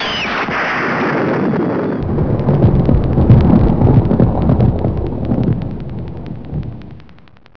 Lightnin
LIGHTNIN.wav